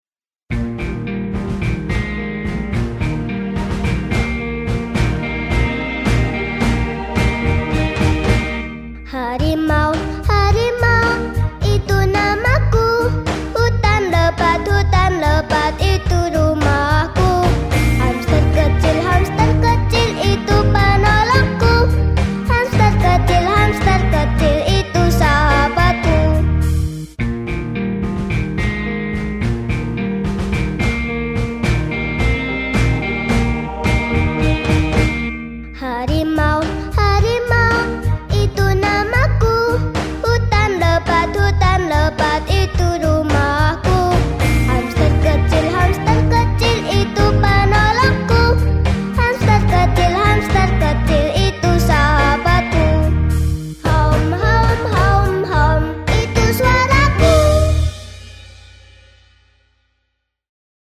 Audio - Harimau Loreng dan Hamster 3 Lagu
5644_Audio__Harimau_Loreng_dan_Hamster_3_Lagu.mp3